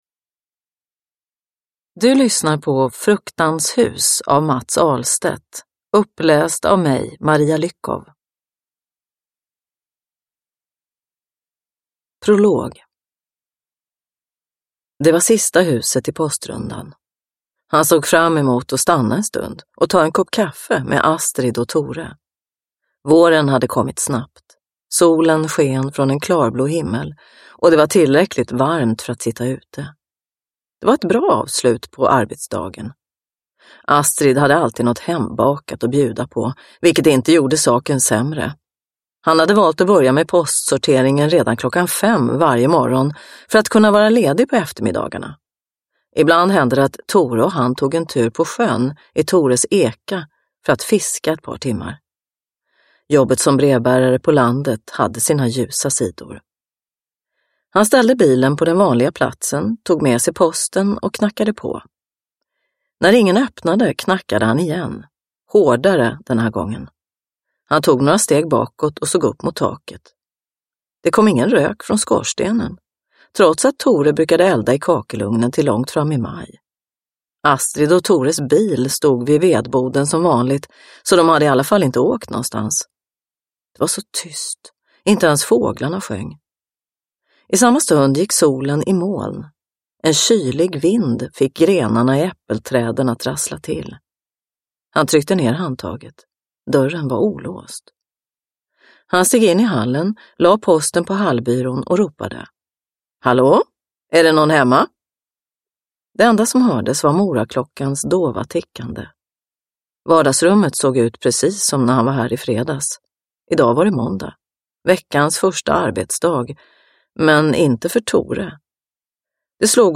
Fruktans hus – Ljudbok – Laddas ner